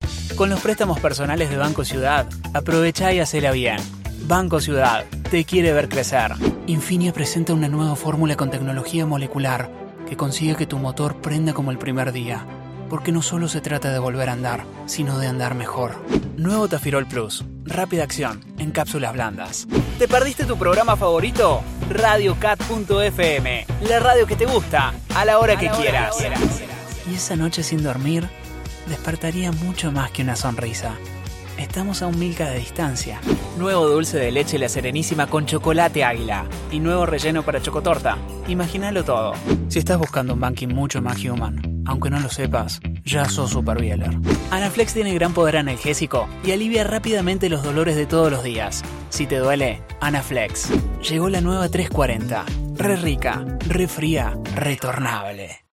Espagnol (argentin)
De la conversation
Content
Amical